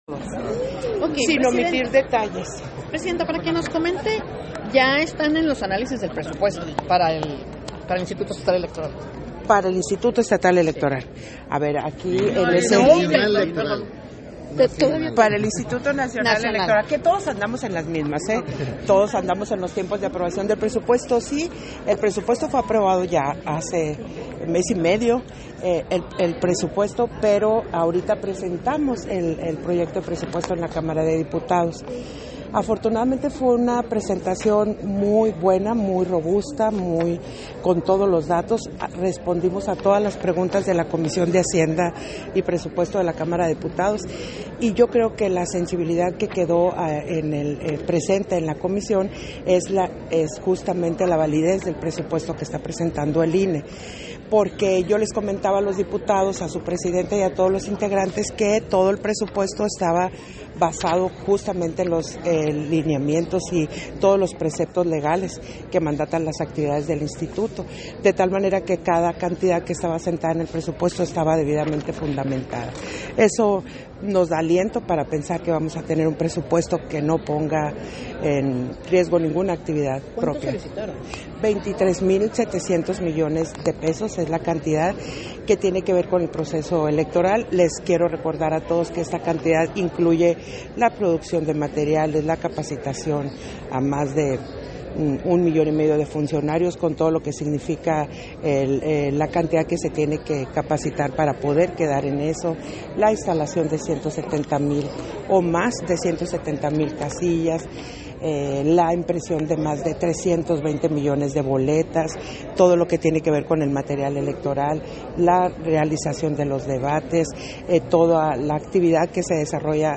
271023_AUDIO_ENTREVISTA-CONSEJERA-PDTA.-TADDEI-SONORA
Versión estenográfica de la entrevista que concedió Guadalupe Taddei, a diversos medios de comunicación